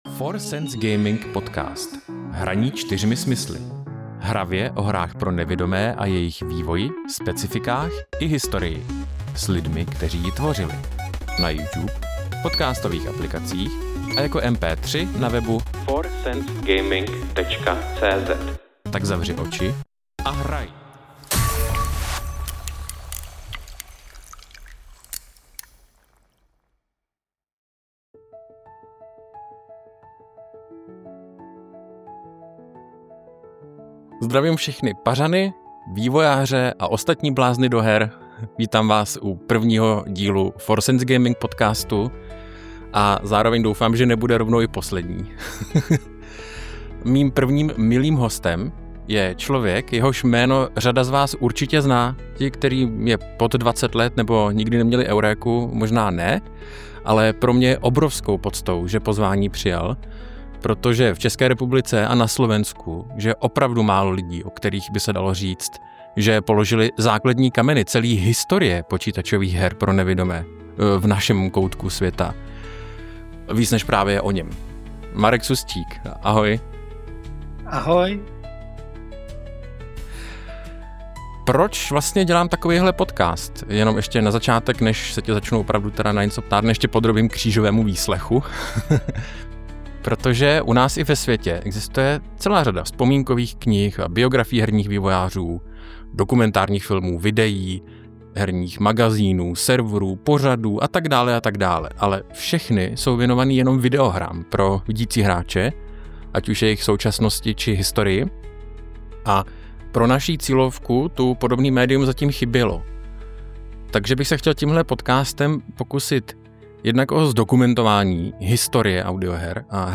Poslechni si první část našeho rozhovoru a dozvíš se to, a ještě mnohem víc ☺ .
Podkresová hudba v úvodní znělce a v představovací části: Vlastní, vytvořená pomocí generativní umělé inteligence Suno